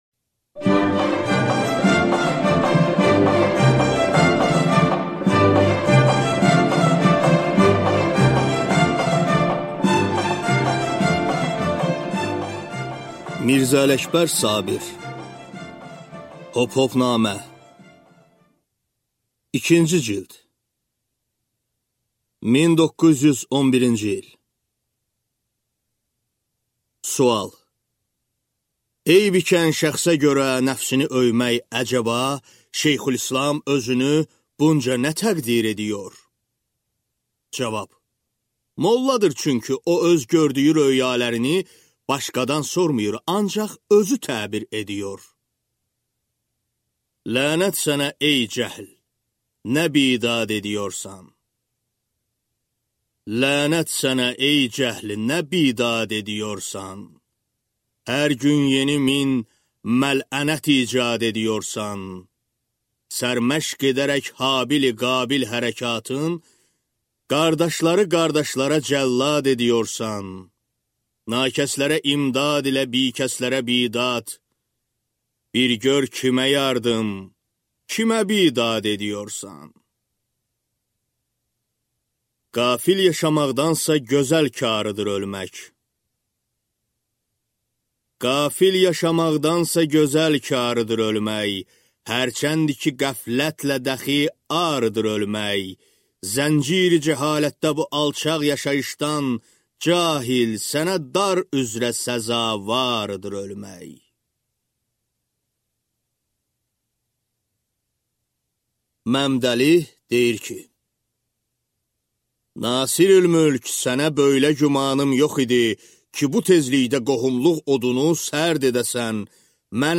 Аудиокнига Hophopnamə 2-ci cild | Библиотека аудиокниг